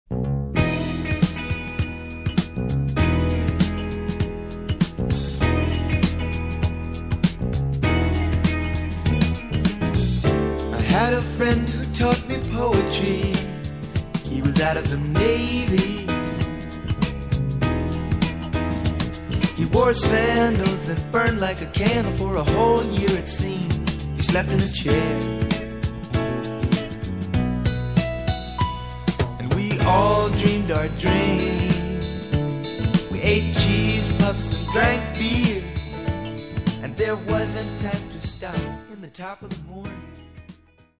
知的で優しいヴォーカルと洗練されたサウンド・プロダクションとのコンビネーションが素晴らしい傑作